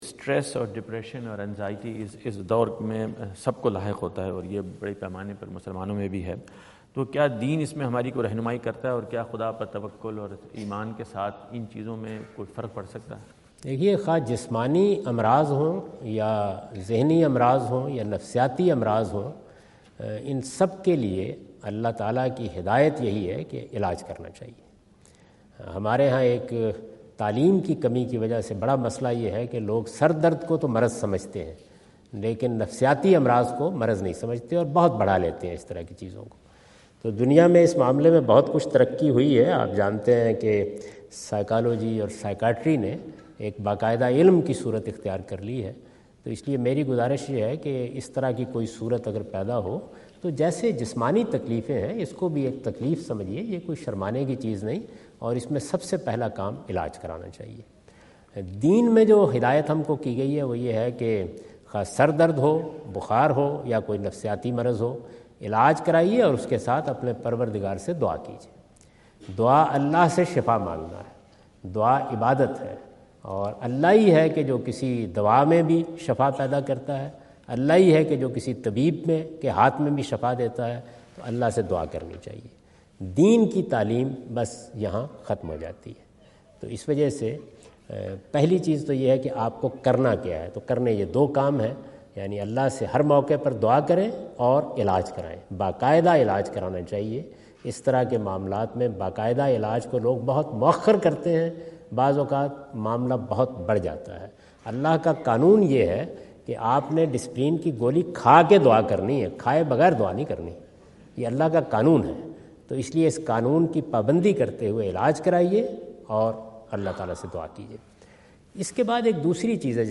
Javed Ahmad Ghamidi answer the question about "Psychological Disorders and Religion" asked at North Brunswick High School, New Jersey on September 29,2017.
جاوید احمد غامدی اپنے دورہ امریکہ 2017 کے دوران نیوجرسی میں "مذہب اور نفسیاتی مسائل" سے متعلق ایک سوال کا جواب دے رہے ہیں۔